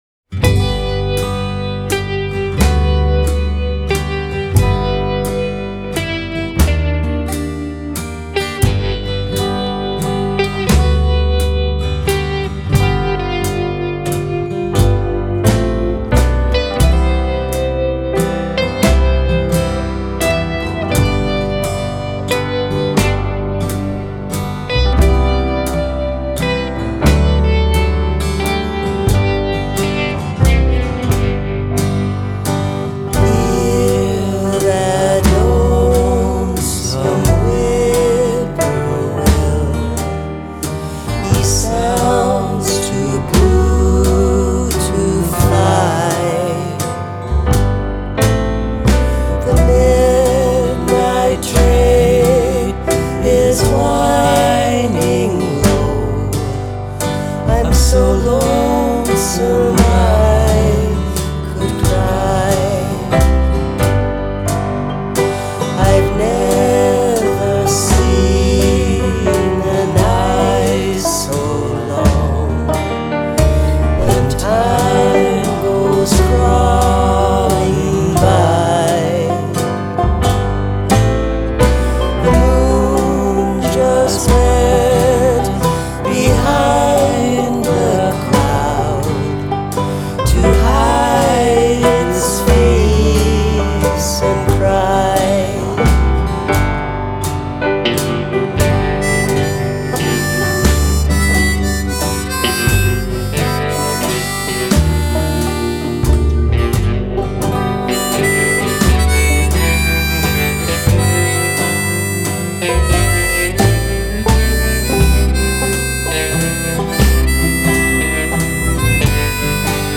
vocals/harmonica
vocals/guitars/banjo/piano
bass/drums